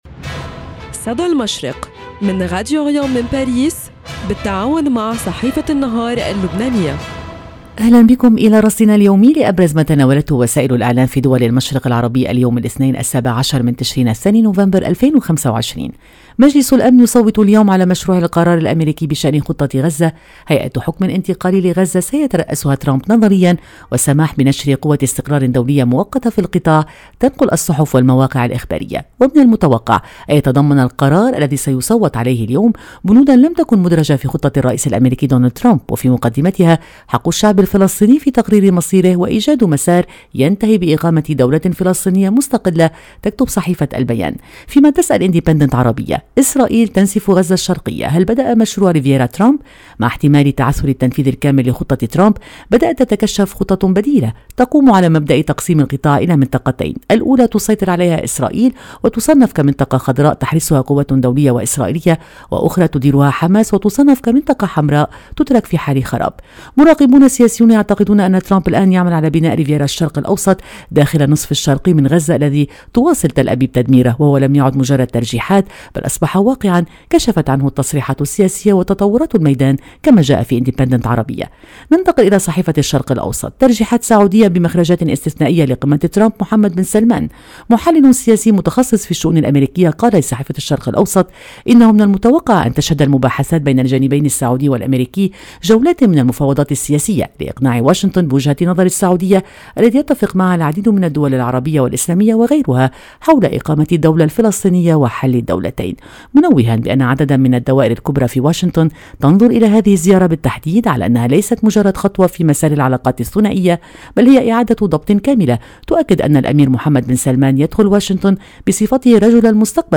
صدى المشرق – نافذتكم اليومية على إعلام الشرق، كل صباح في تعاون بين راديو أوريان إذاعة الشرق من باريس مع جريدة النهار اللبنانية، نستعرض فيها أبرز ما جاء في صحف ومواقع الشرق الأوسط والخليج العربي من تحليلات مواقف وأخبار،  لنرصد لكم نبض المنطقة ونحلل المشهد الإعلامي اليومي.